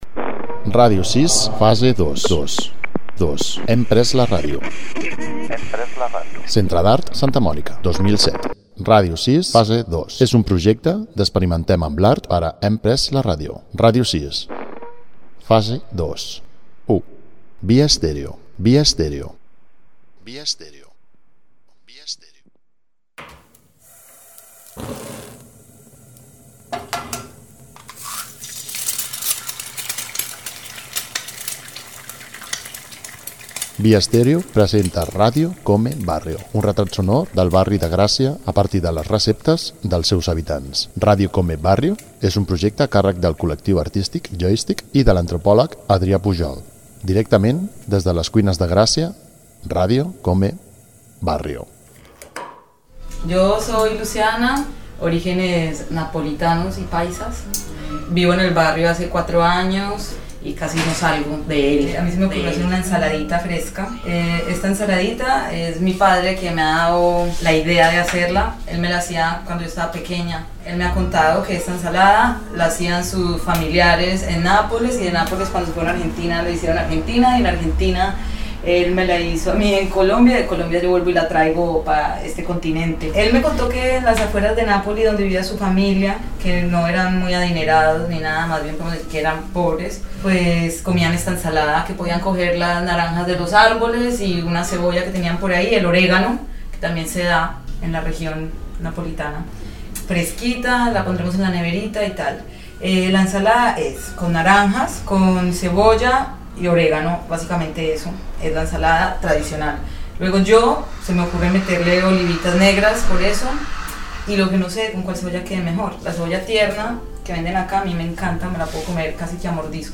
Careta del programa, el projecte "Radio come barrio" fet al barri de Gràcia